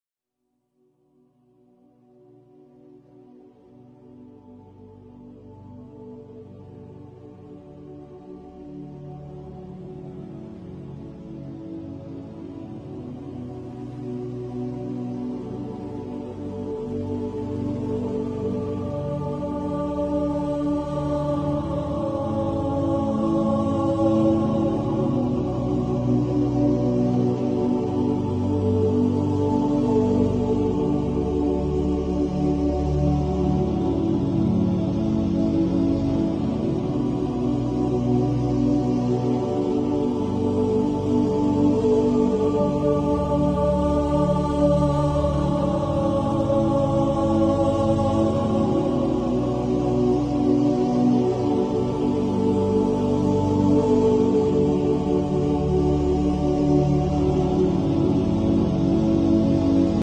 Humming Nasheed sound effects free download